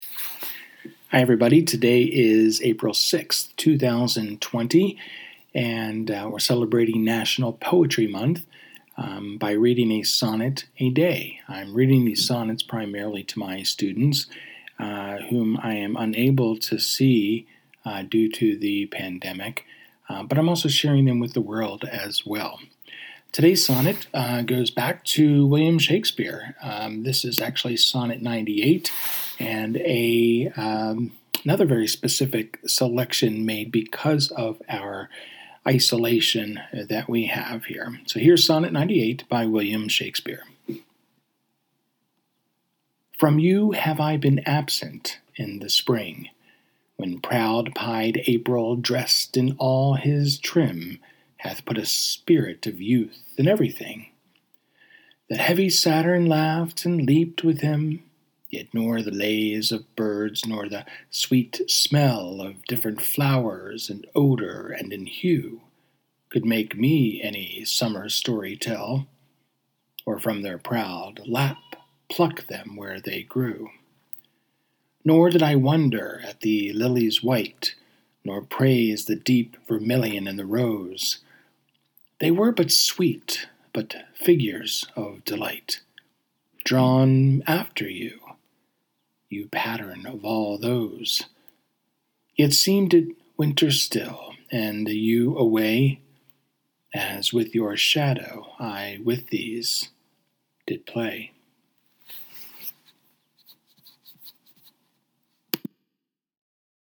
Anyway, today’s reading is Sonnet 98, another appropriate choice for our days of isolation as we stay close together…at a distance.